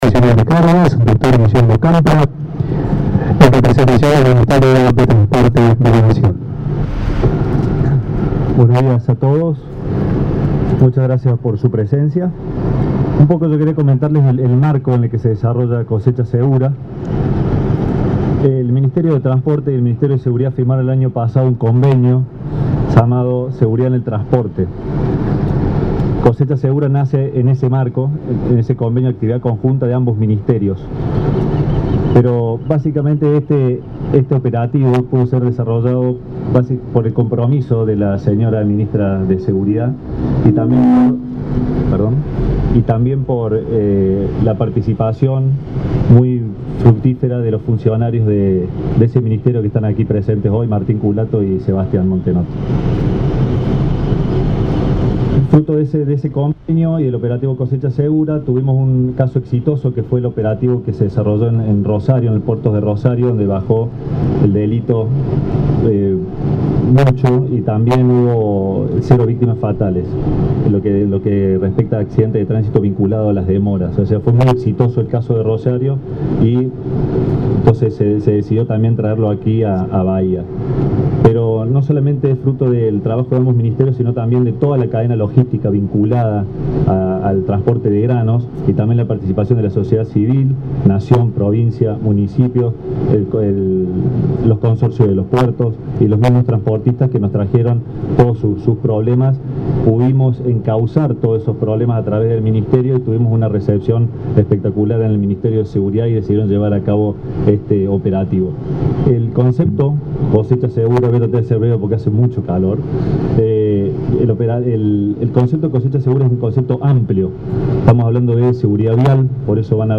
Con la presencia del intendente Héctor Gay esta mañana se realizó el acto formal para presentar el operativo “Cosecha Segura 2017/2018”, iniciativa impulsada por el Ministerio de Seguridad de la Nación con el propósito de optimizar los controles en el período de mayor exportación cerealera.
La ceremonia se desarrolló en la terminal portuaria de la firma Cargill con la participación del director nacional de Cargas del Ministerio de Transporte, Lic. Guillermo Campra; el subsecretario de Logística Federal, Sebastián Montenotte; y el superintendente de Seguridad de la Región Sur, Comisario Mayor Gustavo Maldonado.
Guillermo-Campra-Lanzamiento-del-operativo-“Cosecha-Segura”-en-Bahía-Blanca-11-12.mp3